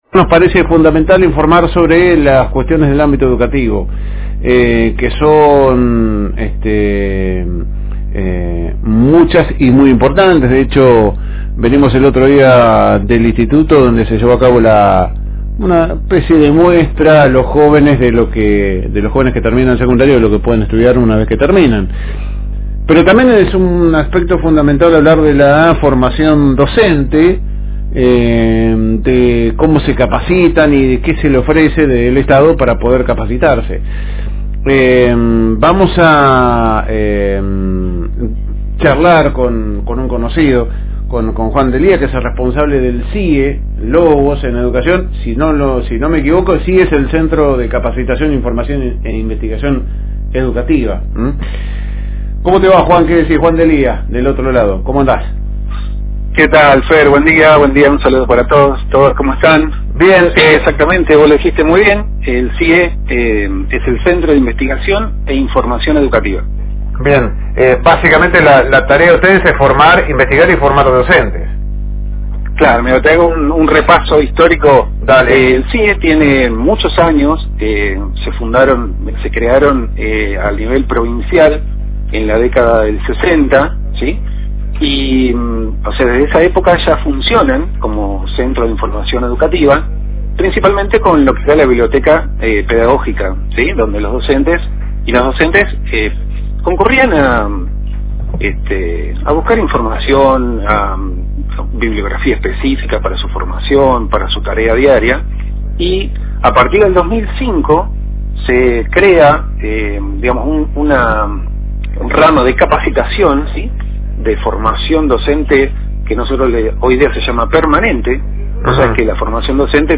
fue entrevistado en el programa radial Esto es Noticia, donde compartió un panorama detallado sobre las actividades que actualmente desarrolla la institución.